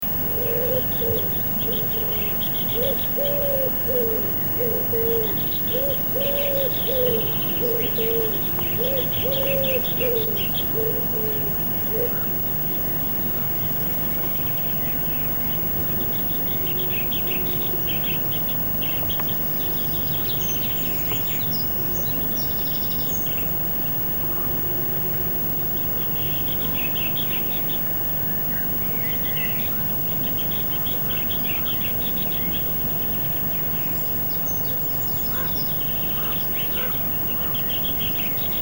/ 906 kb): Sangeren høres i baggrunden. Derudover høres først Ringdue, siden Gærdesmutte og Solsort samt til sidst Ravn .